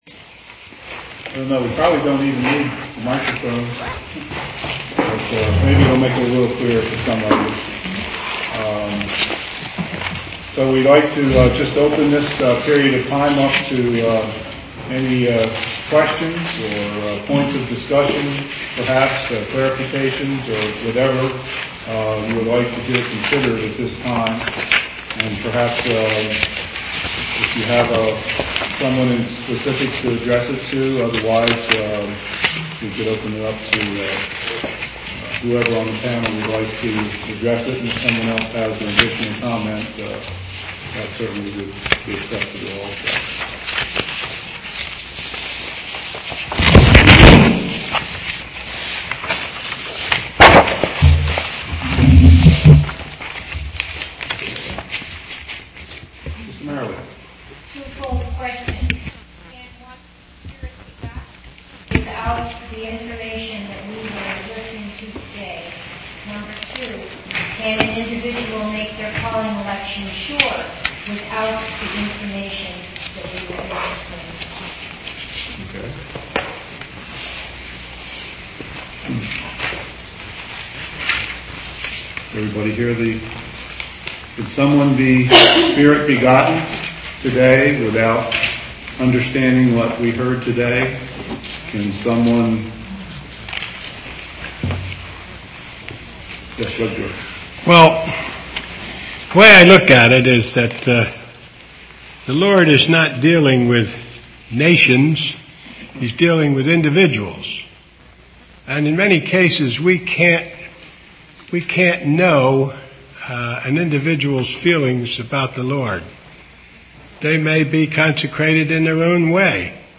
Question_Meeting_-_Four_Ecclesia_Elders.mp3